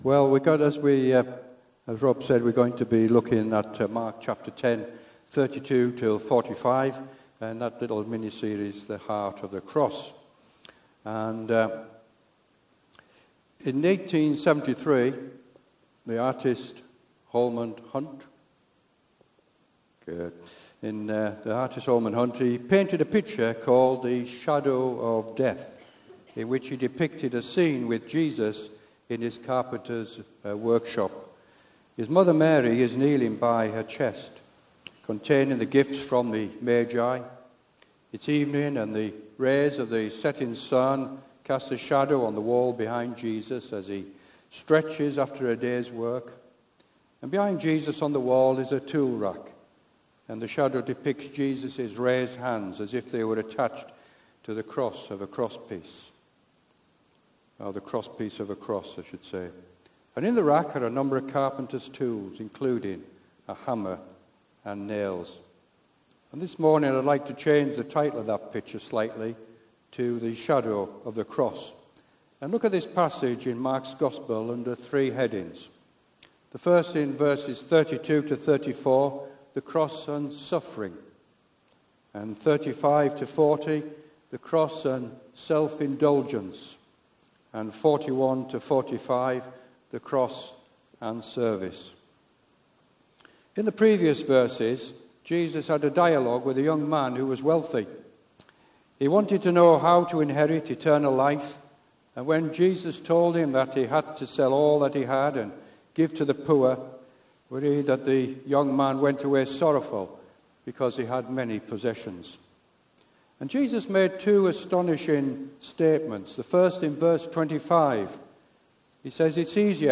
A message from the series "The Heart of the Cross."